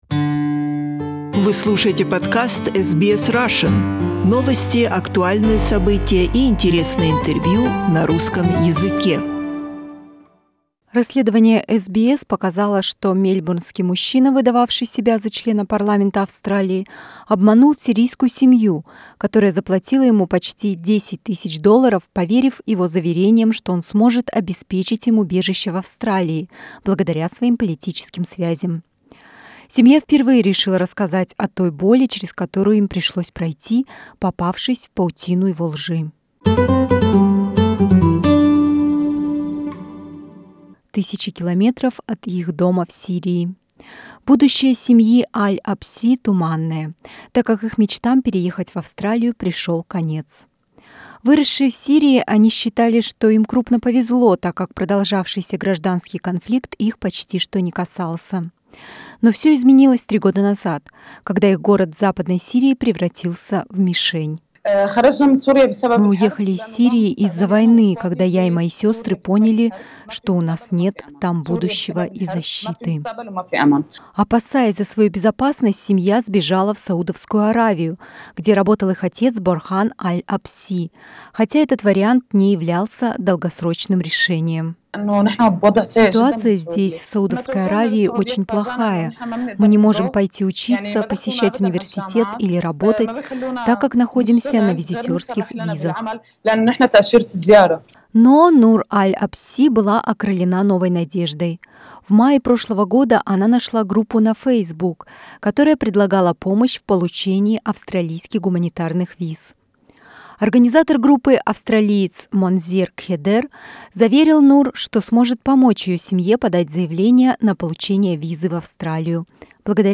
A member of the Syrian family that was allegedly duped talking to SBS from Saudi Arabia Source: SBS